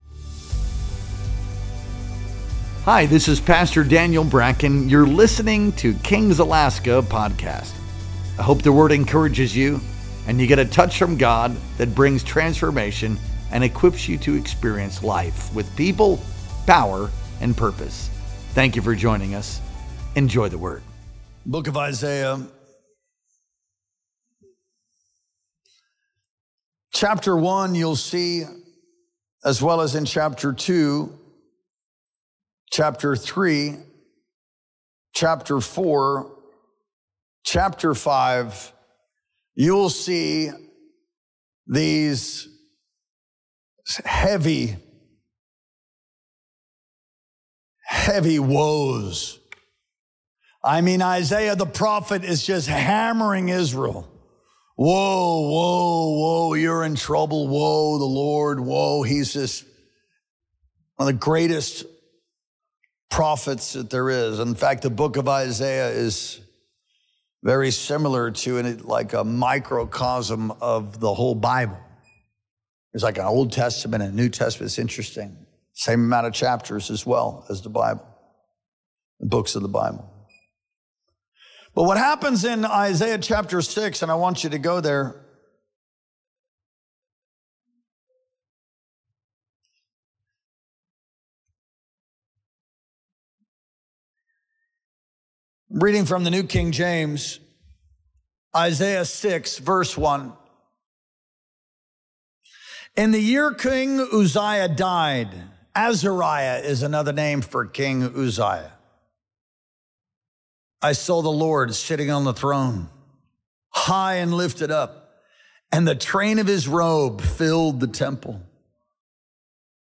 Our Wednesday Night Worship Experience streamed live on July 13th, 2025.